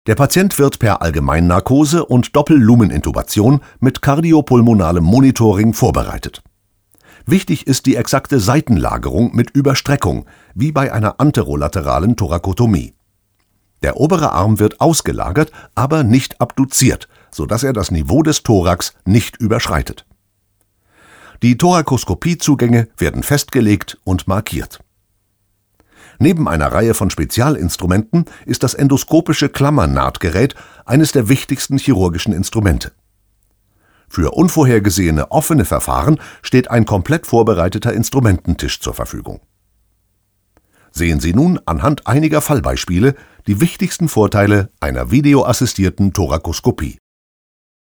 Kein Dialekt
Sprechprobe: Sonstiges (Muttersprache):
german voice over artist